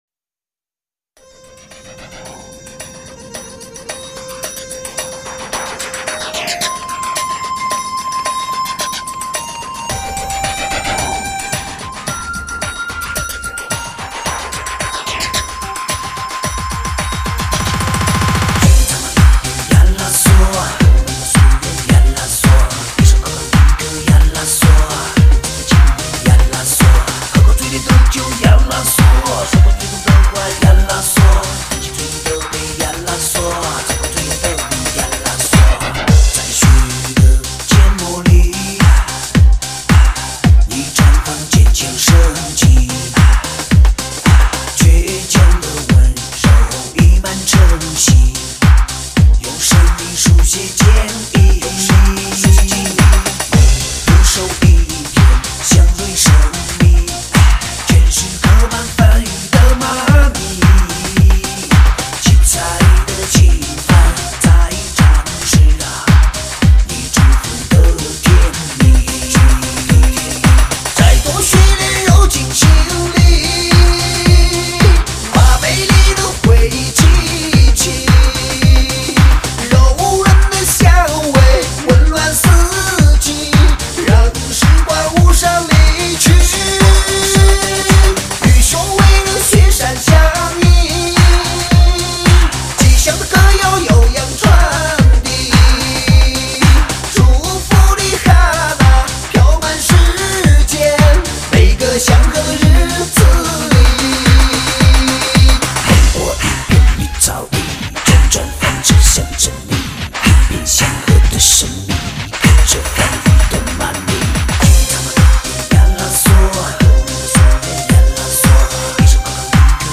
唱片类型：电音炫音
很嗨的舞动狂欢曲，谢谢一如既往的精彩分享........
强劲有力的节奏。